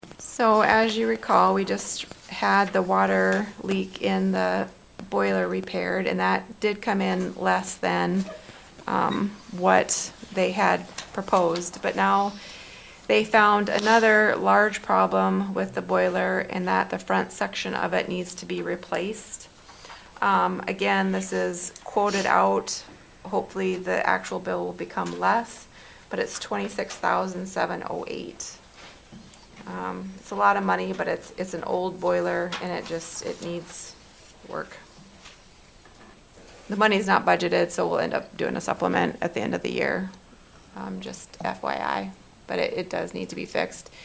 Also at Wednesday’s council meeting: